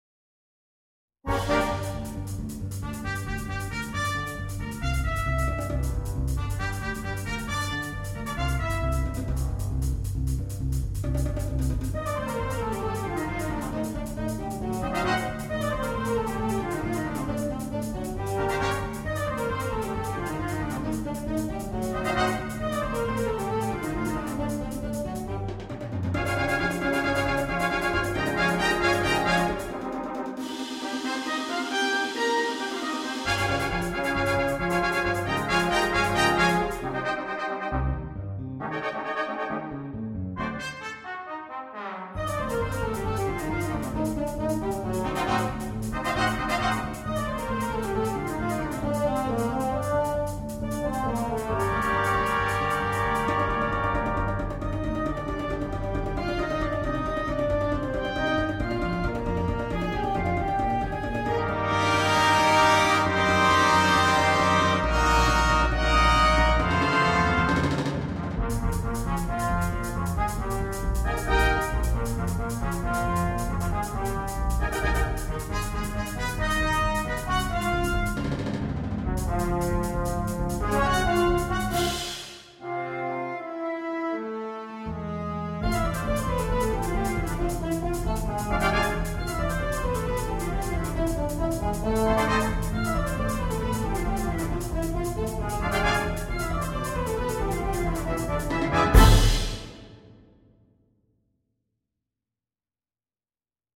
для духового оркестра